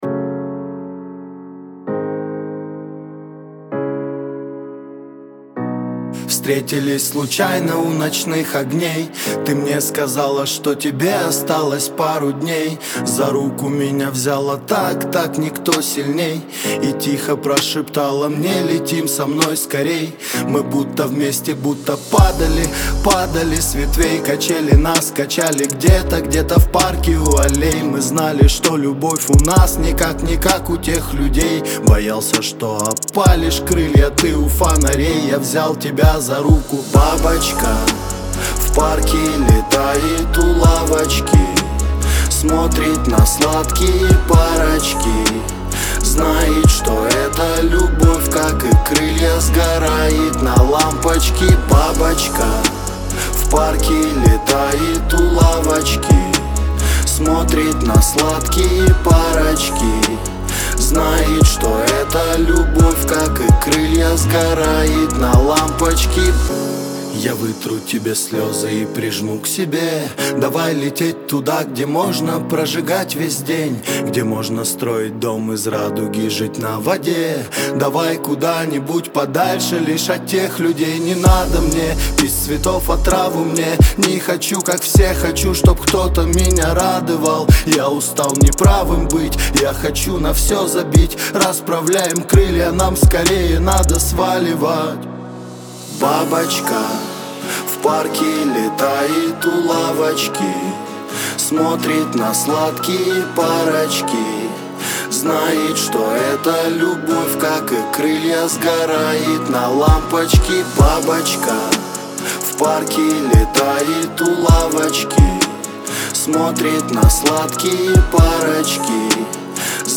Эмоциональное исполнение и запоминающийся припев